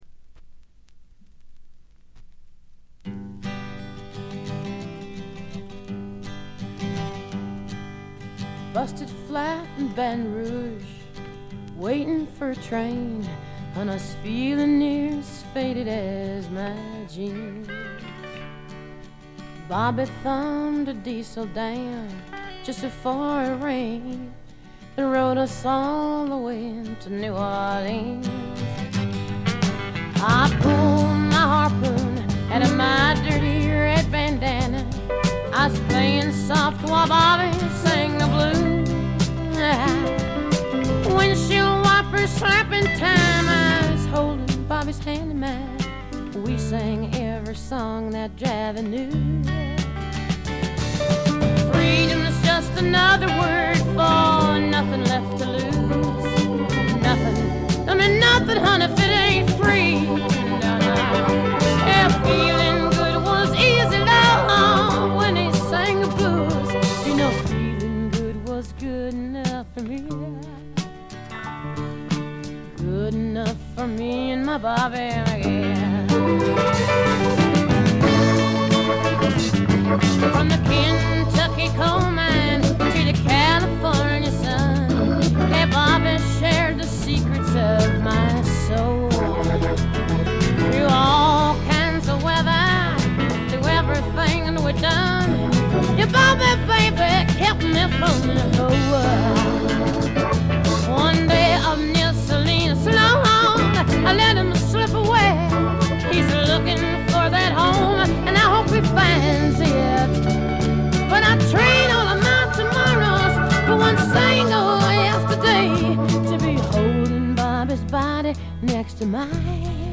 これ以外は細かなバックグラウンドノイズ、チリプチ少々。
試聴曲は現品からの取り込み音源です。